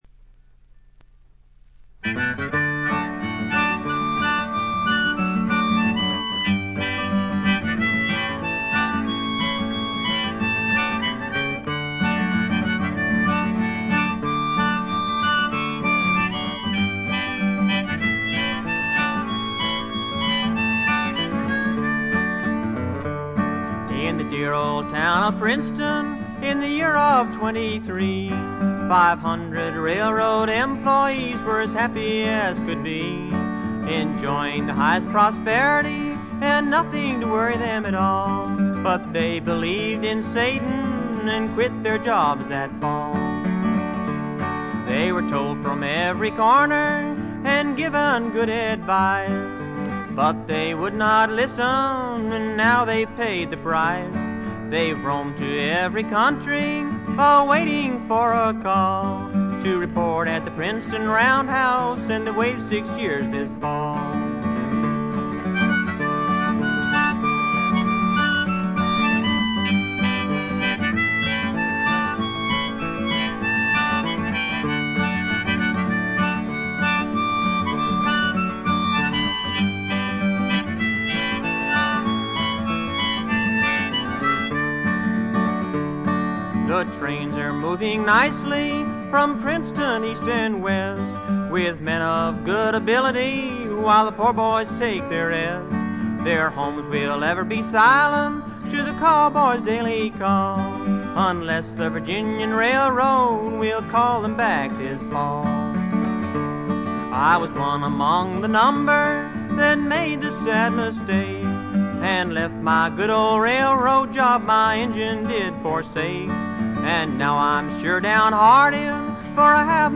原初のブルーグラスの哀愁味あふれる歌が素晴らしいです！
試聴曲は現品からの取り込み音源です。